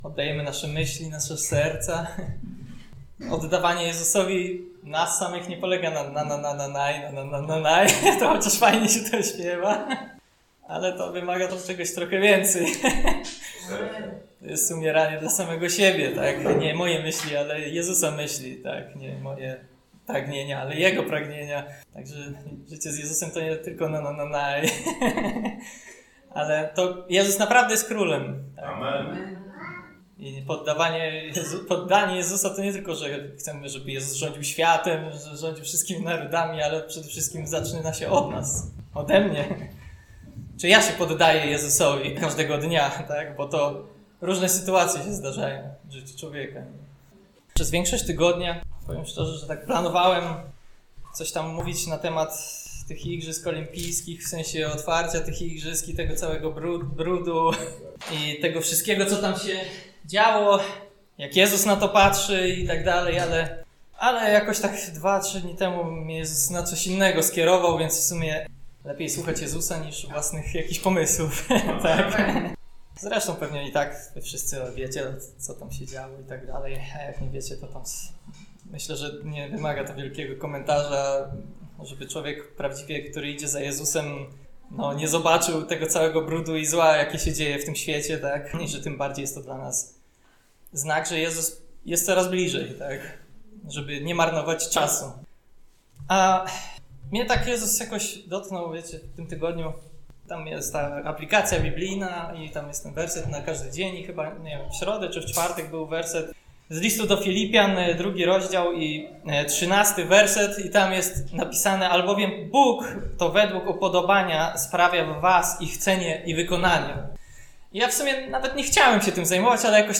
Posłuchaj kazań wygłoszonych w Zborze Słowo Życia w Olsztynie.